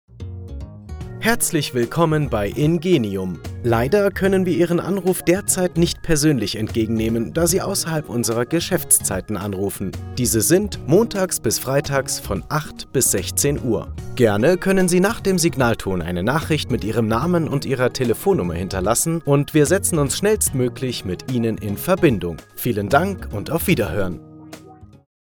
Professioneller Sprecher & Moderator
Mein hauseigenes Studio in Broadcast-Qualität sorgt für exzellente Ergebnisse bei vielfältigen Projekten.
5 | Anrufbeantworter